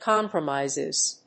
/ˈkɑmprʌˌmaɪzɪz(米国英語), ˈkɑ:mprʌˌmaɪzɪz(英国英語)/